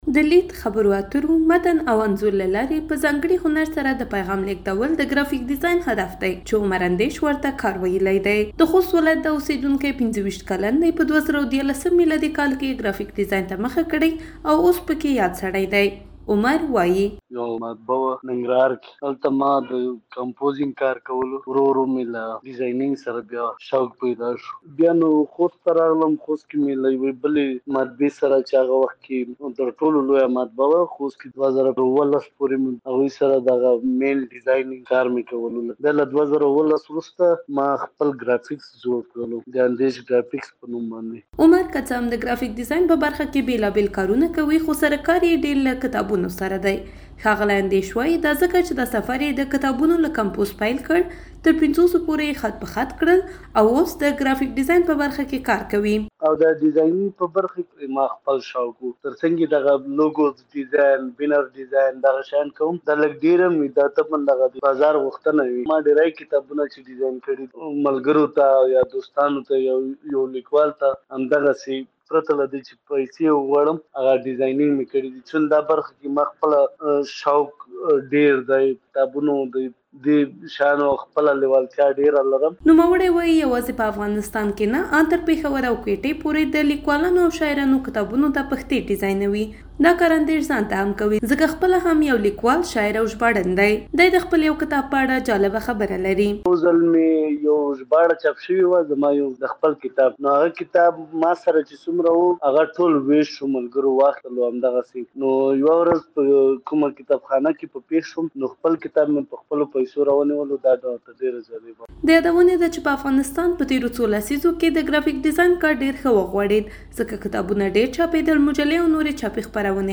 د ګرافیک ډیزاینر راپور